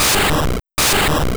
Sounds / Battle / Attacks / Normal / Bind.wav